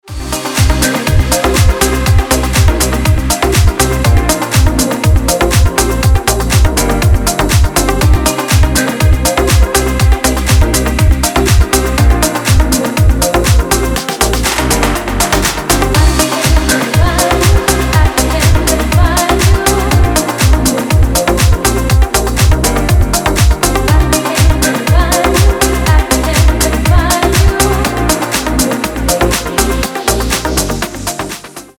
• Качество: 320, Stereo
ритмичные
EDM
Стиль: house